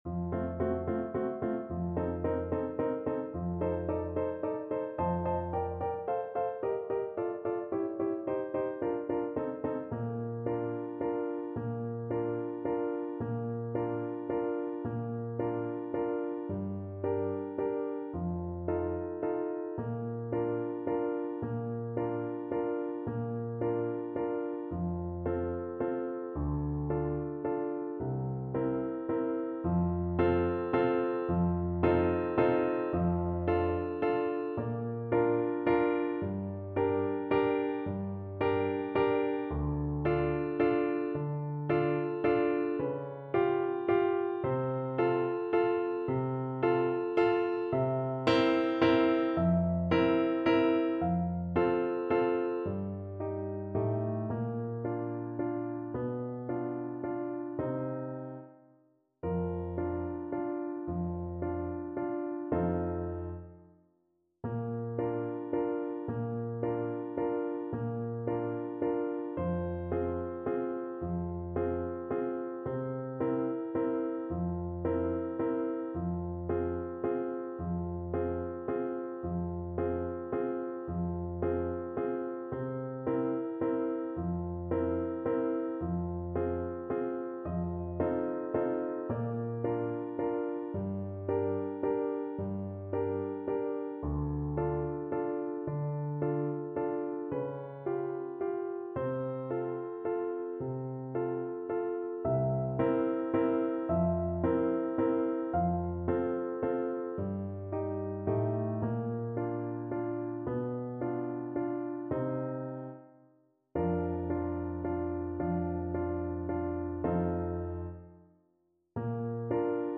Trombone version
3/4 (View more 3/4 Music)
Classical (View more Classical Trombone Music)